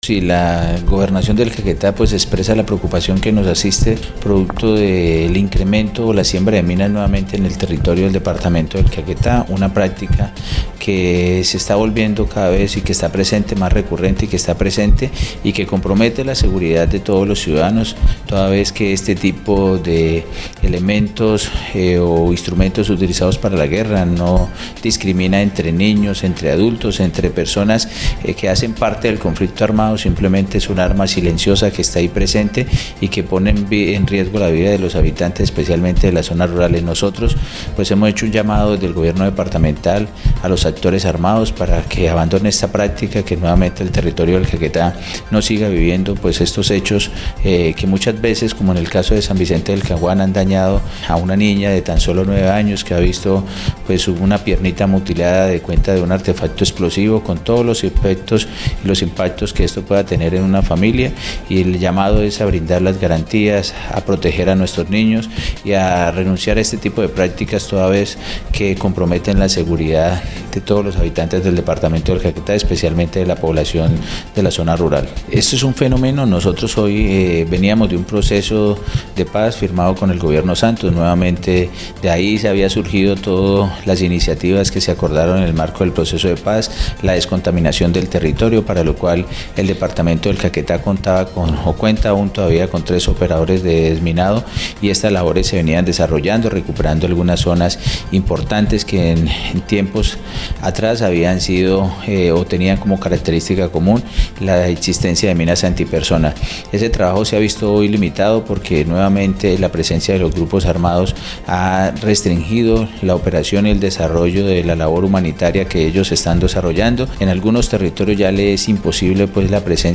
Arturo Perdomo Granja, secretario de gobierno departamental, explicó que esta práctica vuelve a ser retomada por los grupos armados ilegales, incluso, impiden que los grupos especiales de desminado humanitario, ingresen a zonas puntuales a realizar su trabajo.